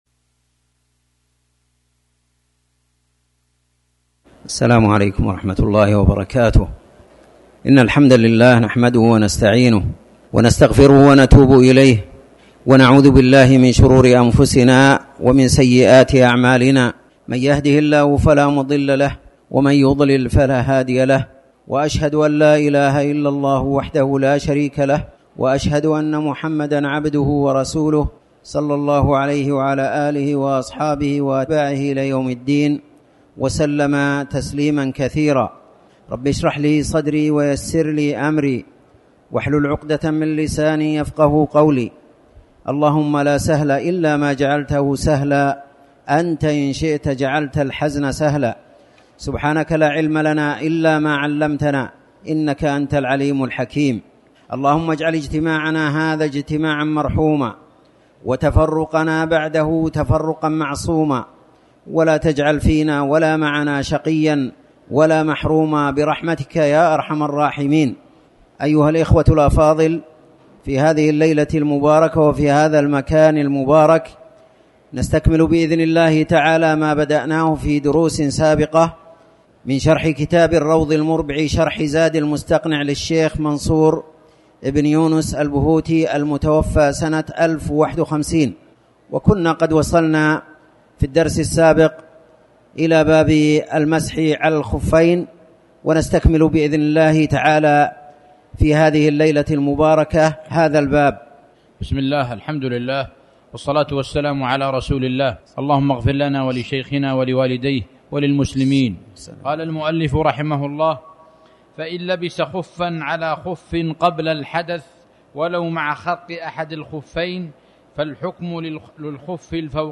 تاريخ النشر ١٣ صفر ١٤٤٠ هـ المكان: المسجد الحرام الشيخ